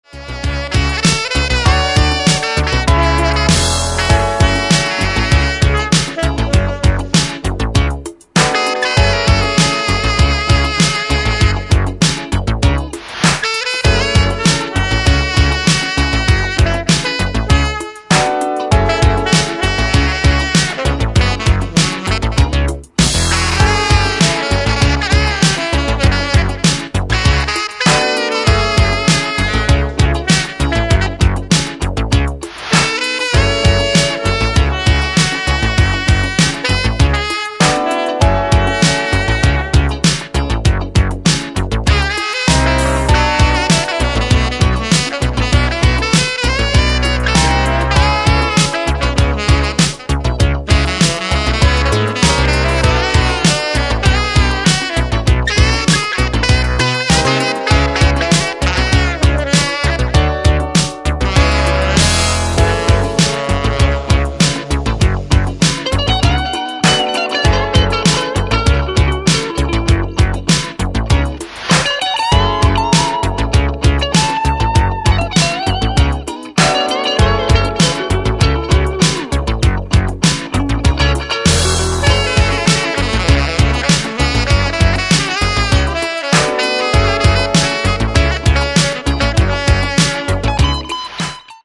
Electro tinged street soul
tenor sax
guitar
DIY living room booth
punchy synth bass